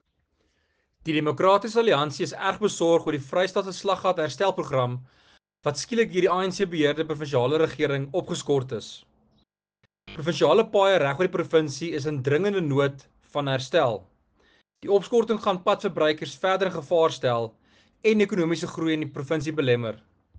Afrikaans soundbite by Werner Pretorius MPL with image here